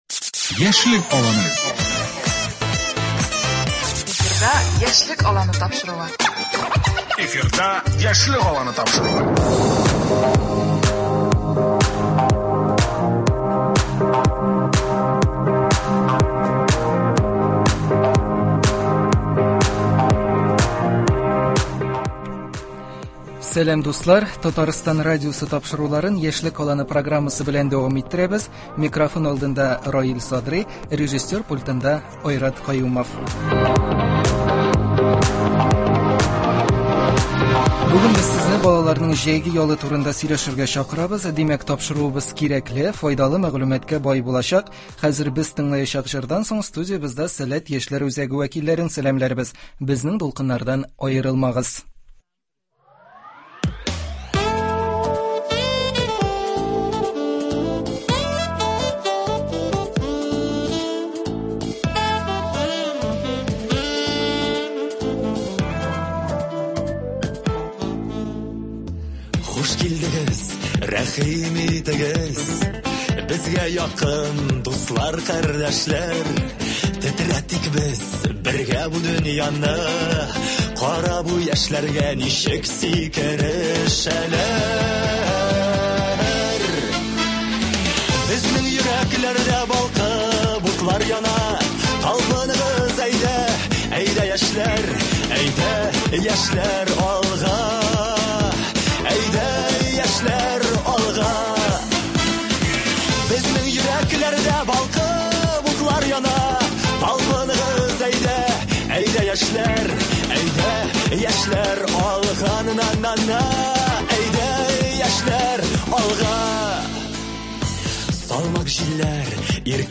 Бүген сезне балаларның җәйге ялы турында сөйләшергә чакырабыз. Димәк, тапшыруыбыз кирәкле, файдалы мәгълүматка бай булачак.